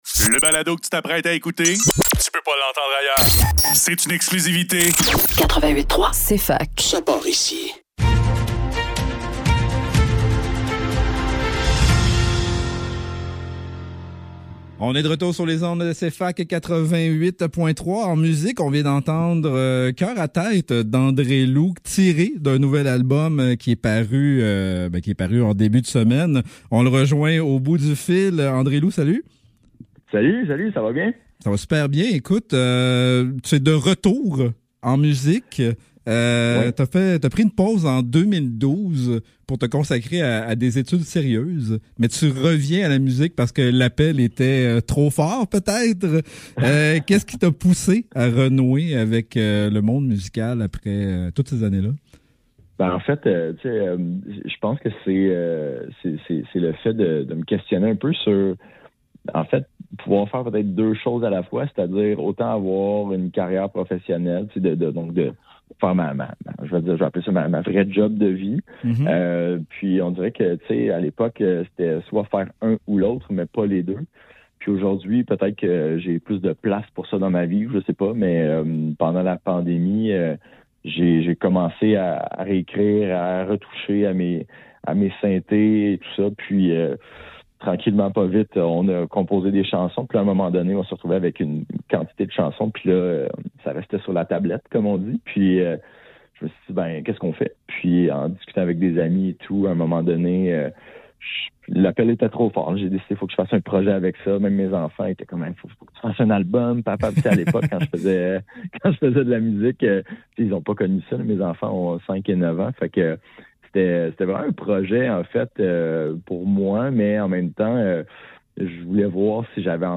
Le Neuf - Entrevue